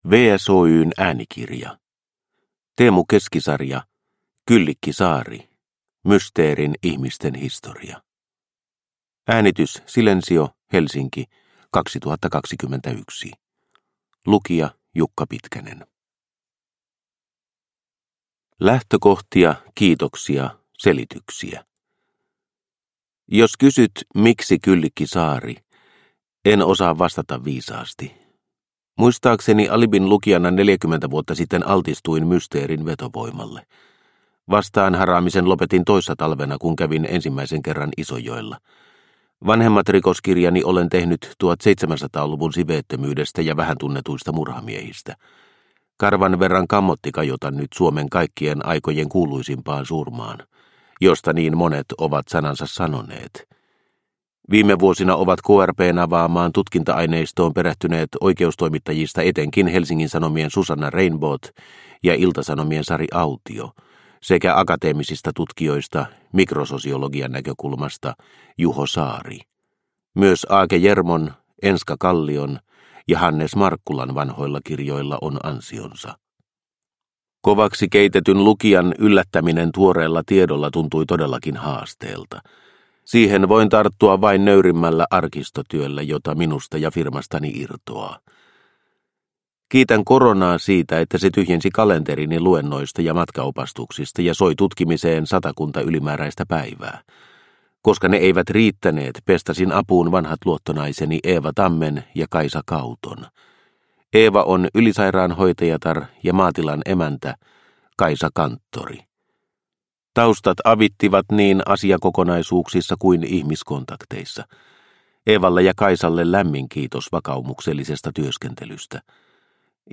Kyllikki Saari – Ljudbok – Laddas ner